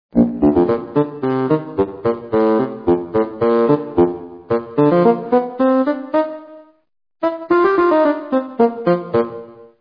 Fagotto
Pastoso, ritmico, struggente, penetrante.
fagott.mp3